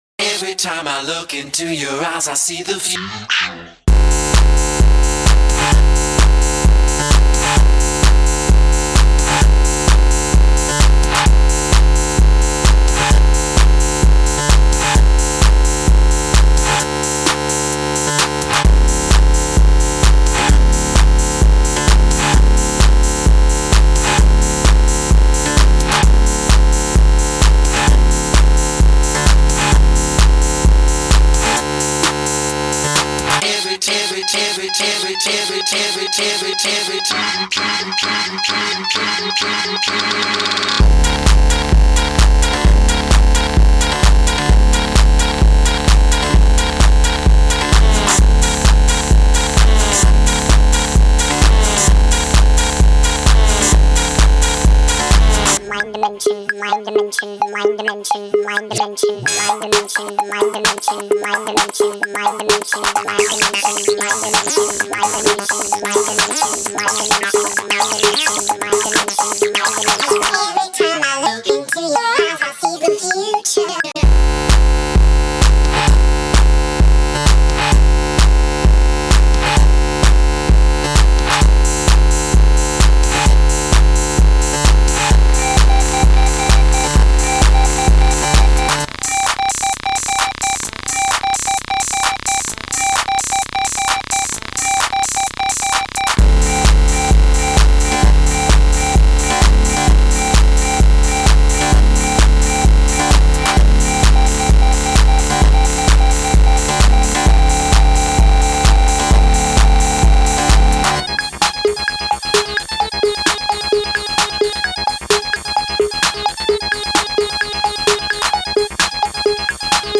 screeching metal and needling beats